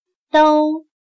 /dōu/Todo; todos